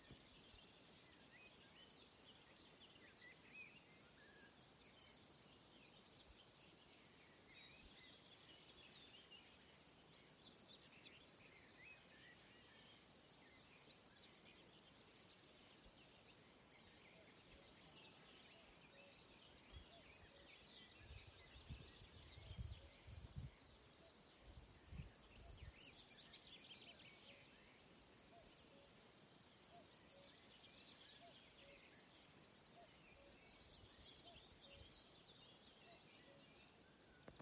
Птицы -> Славковые -> 2
речной сверчок, Locustella fluviatilis
СтатусПоёт